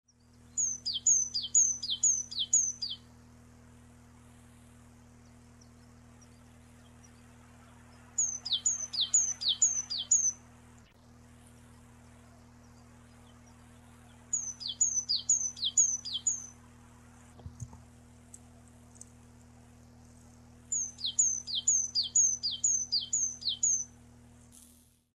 Синехвостка (позывка)
10_16_06_93Tcyanurus_IX19.mp3, 160812 байт
Тревожная позывка. Голоустнинский тракт. 16.06.1993.